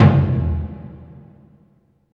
Index of /90_sSampleCDs/Roland LCDP14 Africa VOL-2/PRC_Burundi Drms/PRC_Burundi Drms